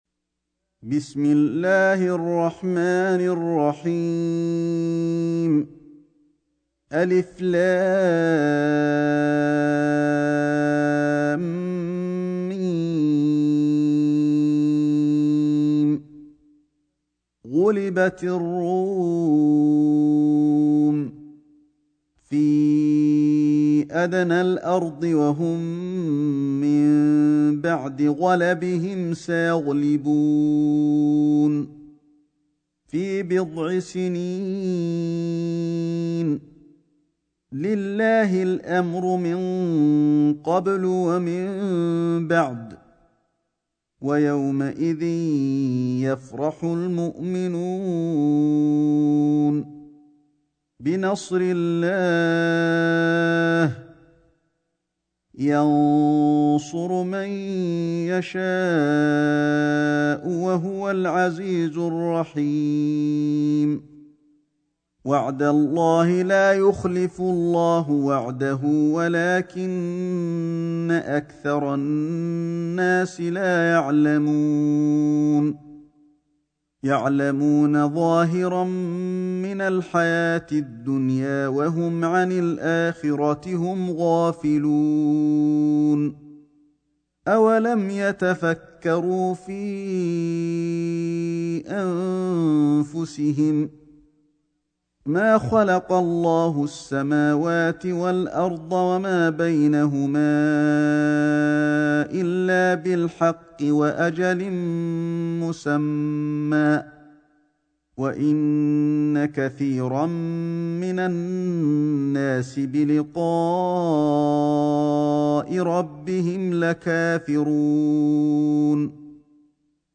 سورة الروم > مصحف الشيخ علي الحذيفي ( رواية شعبة عن عاصم ) > المصحف - تلاوات الحرمين